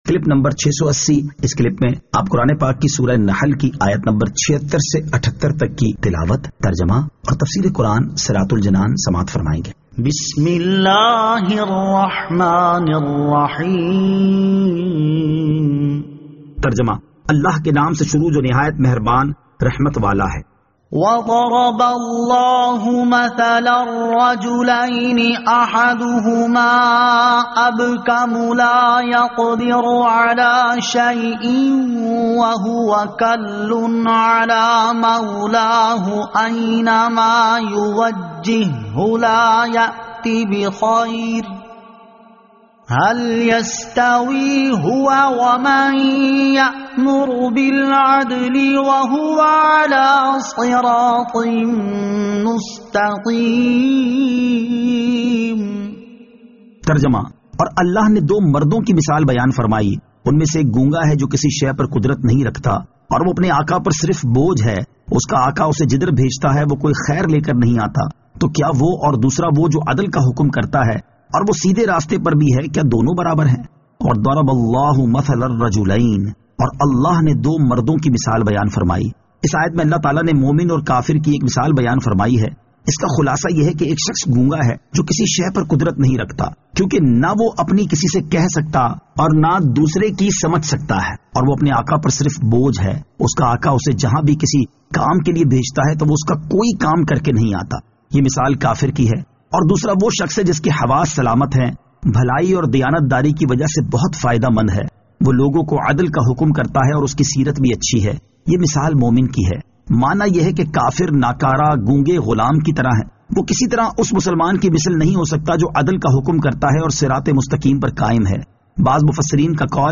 Surah An-Nahl Ayat 76 To 78 Tilawat , Tarjama , Tafseer